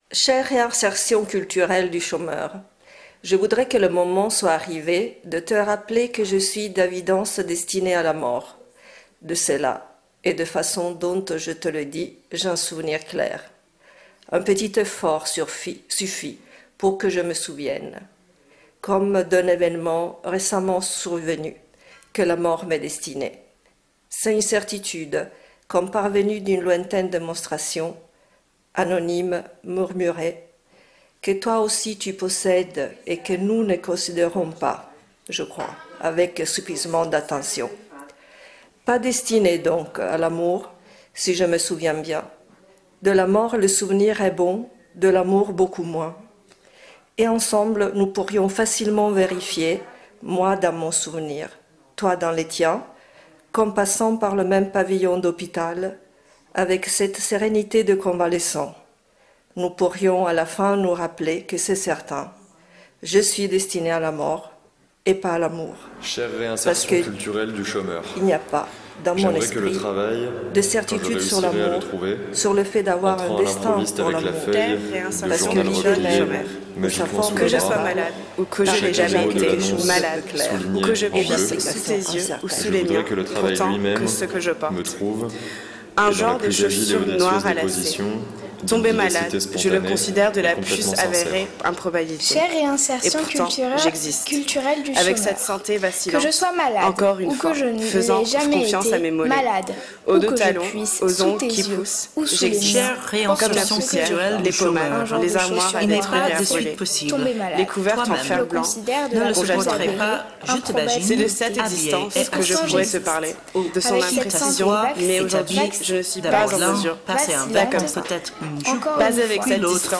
Lettere alla Reinserzione Culturale del Disoccupato ° installazione sonora (13/6/2015, Parigi)
Voci registrate a Berlino, Parigi, Milano, Champigny, Albinea, Verberie, Venezia, Torino, Martinafranca tra giugno 2014 e maggio 2015. Montaggio sonoro